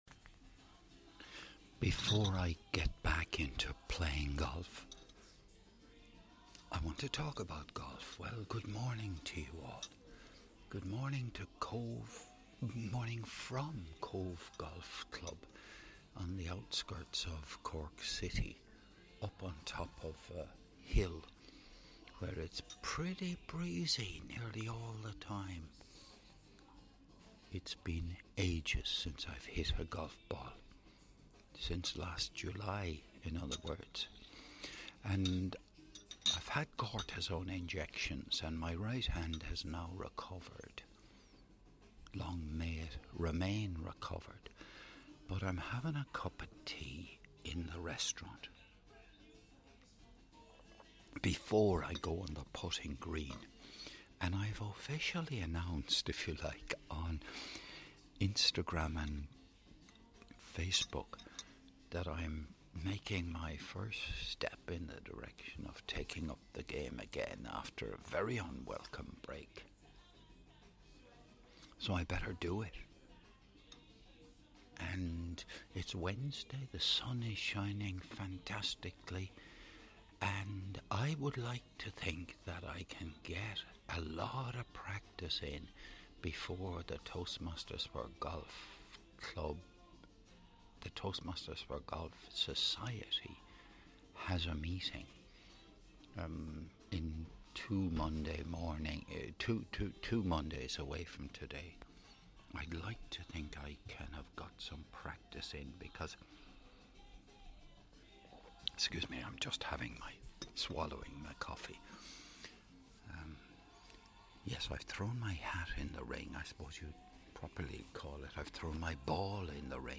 This was recorded in the restaurant of CobhGolf Club as about 930am on Wednesday seventh of May 2025. I was having a cup of tea before going to practice putting.